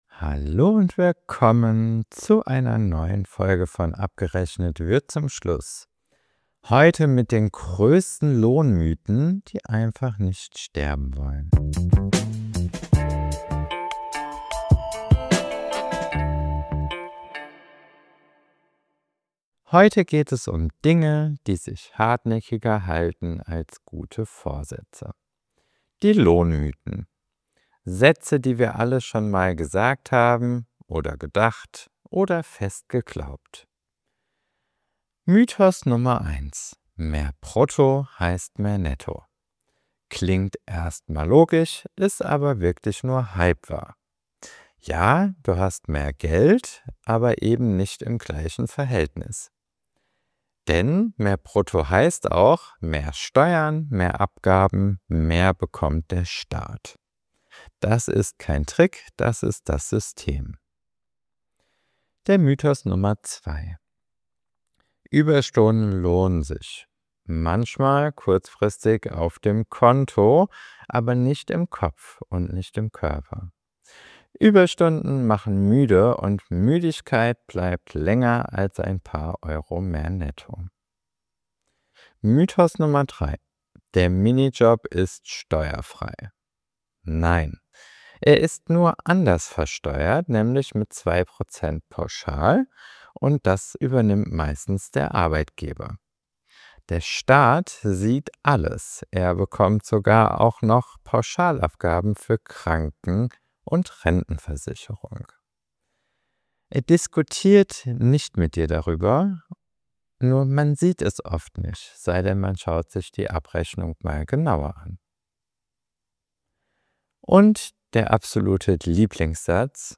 – ruhig, ehrlich und mit einem Augenzwinkern.